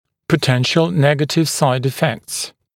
[pə’tenʃl ‘negətɪv saɪd ɪ’fekts][пэ’тэншл ‘нэгэтив сайд и’фэктс]потенциальные негативные побочные эффекты